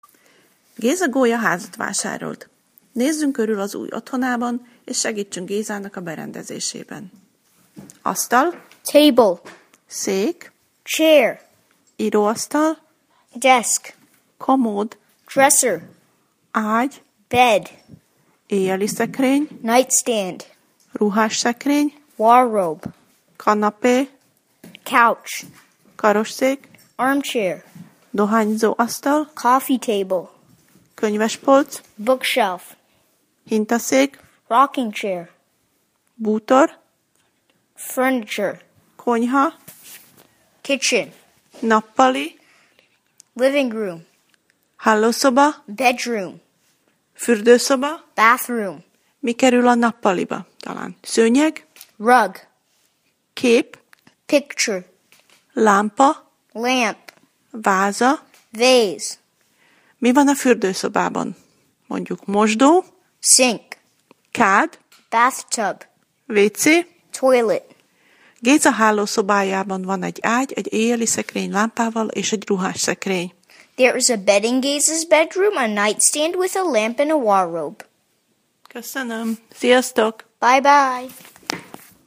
A hangos szótár eléréséhez kattints